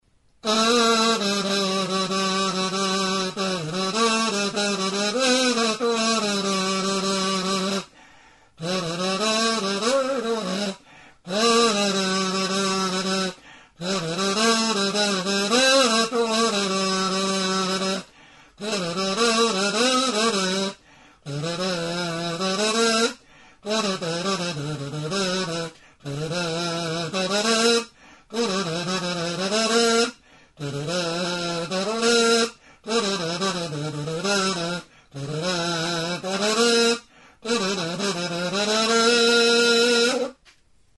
Membranophones -> Mirliton
Recorded with this music instrument.
Bi aldeetatik irekia dagoen kanaberazko tutua da.